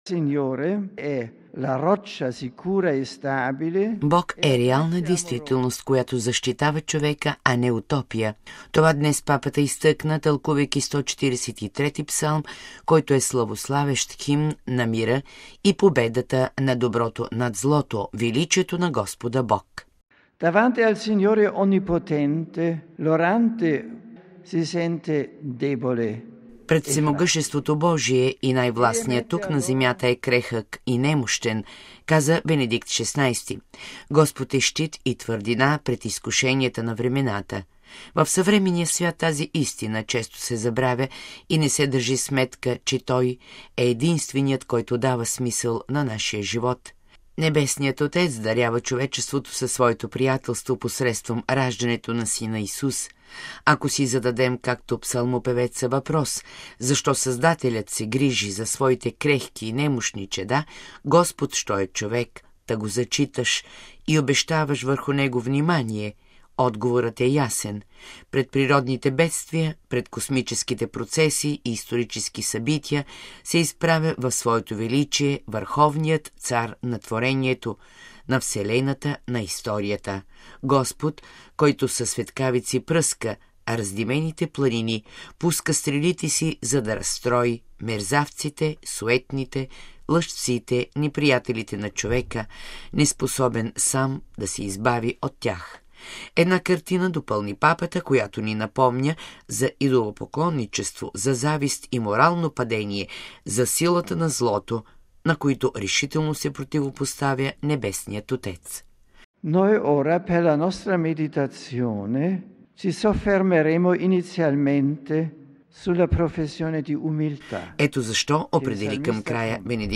Аудиенцията се проведе във ватиканската аула Павел VІ в присъствието на осем хиляди поклонници от Италия,Франция, Швейцария, САЩ, Бразилия, Испания, Полша и Хърватска.